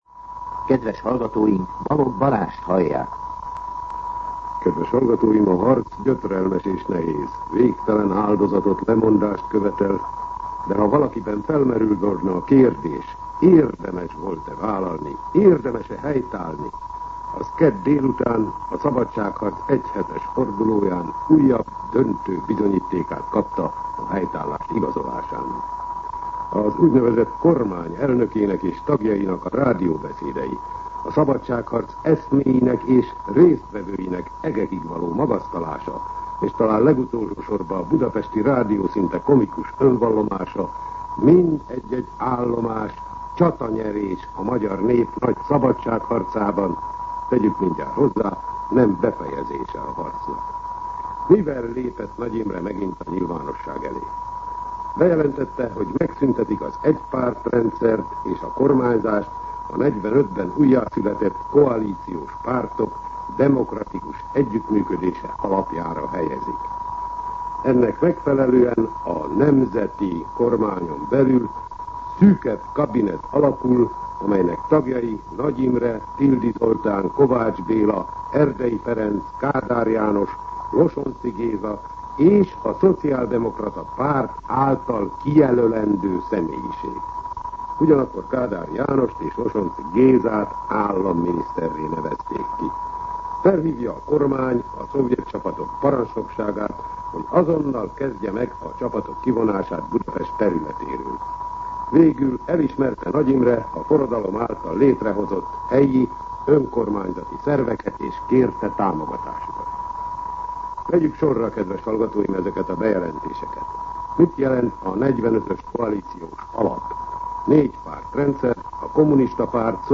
Rendkívüli kommentár